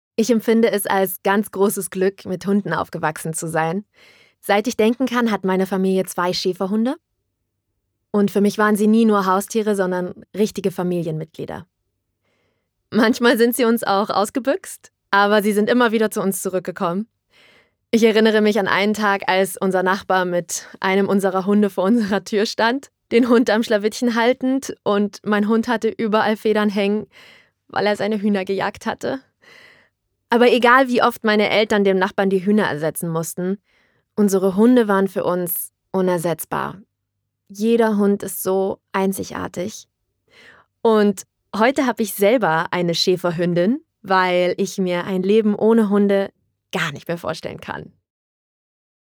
sehr variabel
Jung (18-30)
Sächsisch
natürliche Stimme